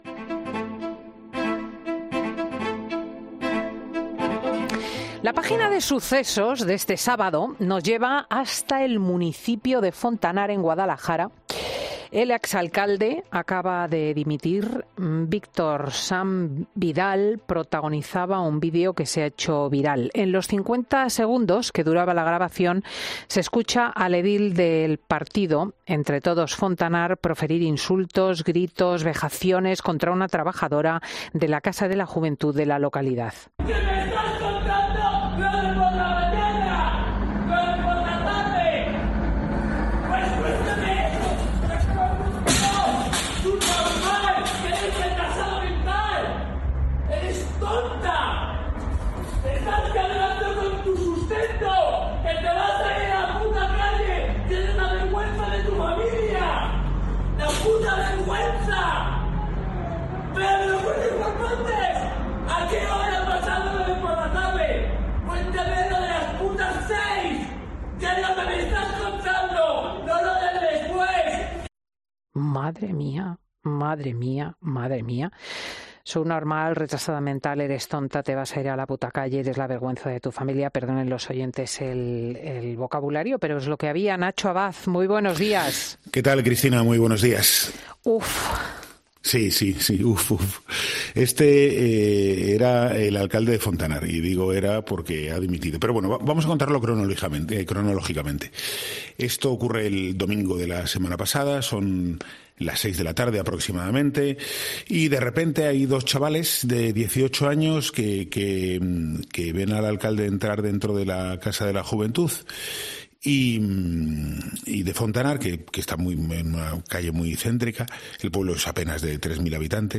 Es lo que ha explicado, en Fin de Semana, el periodista de sucesos Nacho Abad a Cristina López Schlichting.